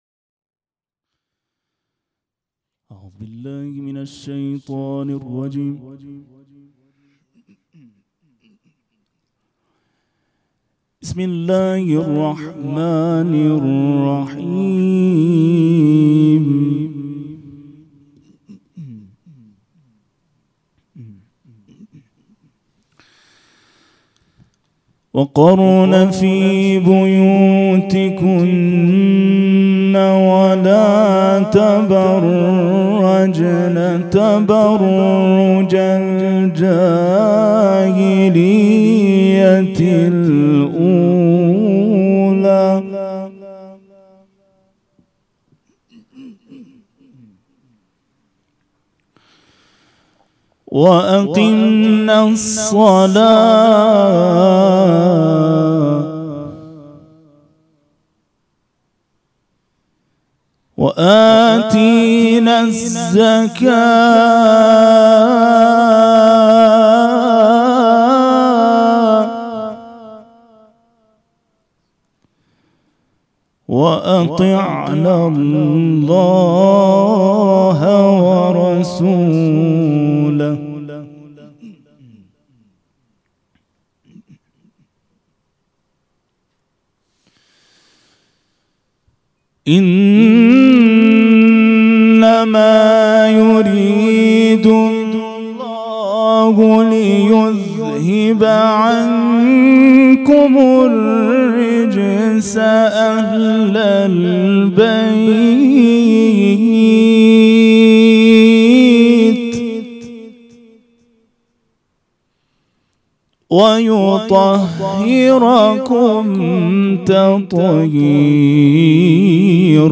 خیمه گاه - هیئت مکتب الزهرا(س)دارالعباده یزد - تلاوت مجلسی